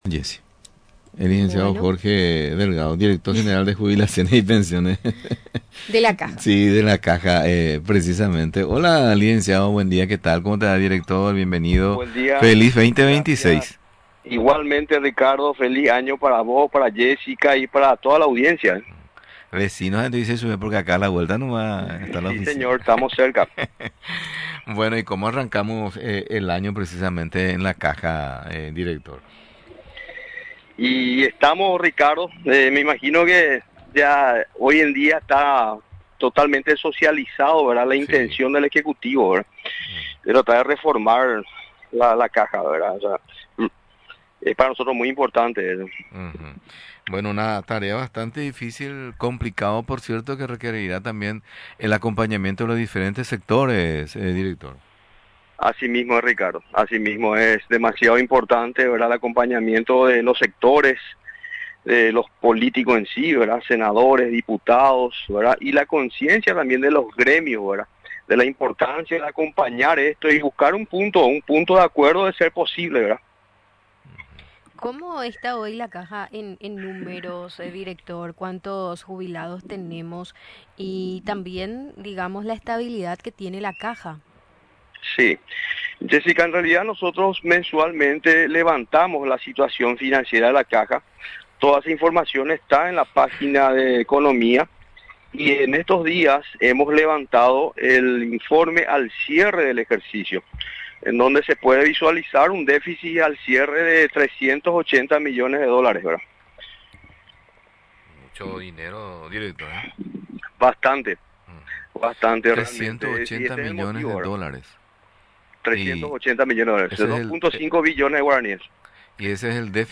Durante la entrevista en Radio Nacional del Paraguay, explicó que éste déficit es de larga data, y que ésta información se confirma al cierre del período 2025.